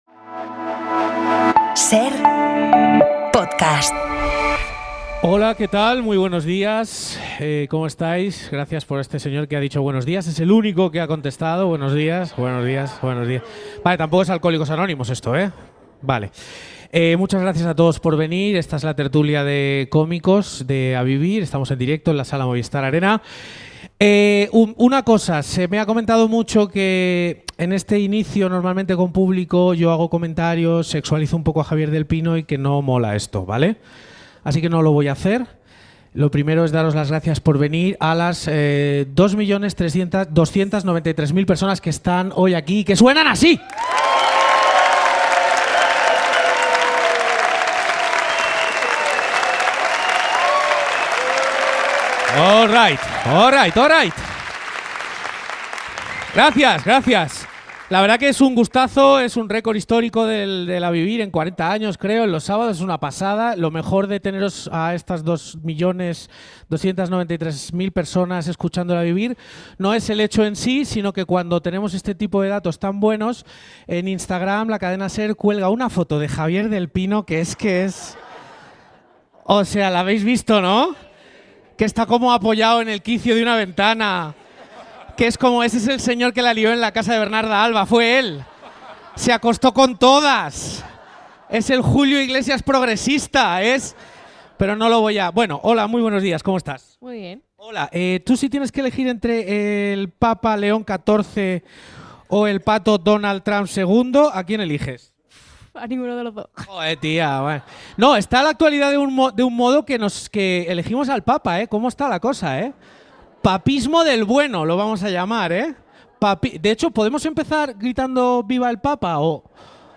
desde La Sala de la Comedia By Domino's en el Movistar Arena